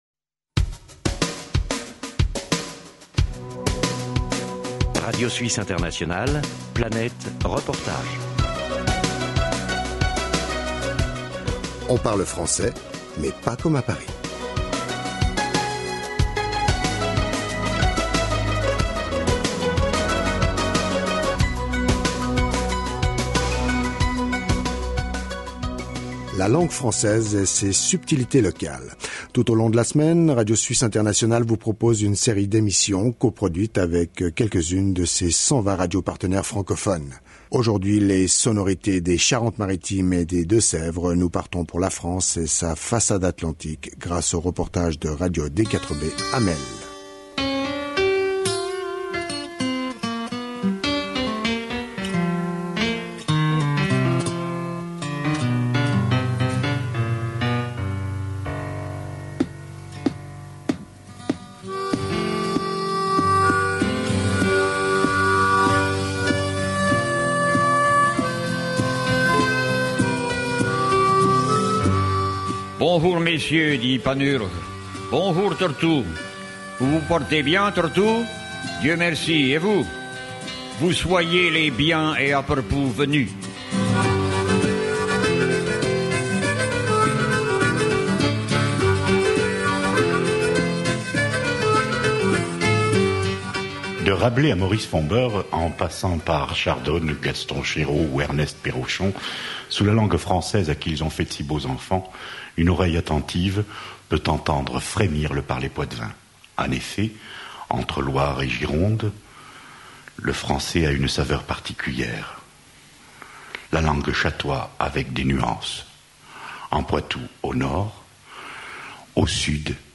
sur les ondes de Radio D4B à Melle, à l’ouest de la France.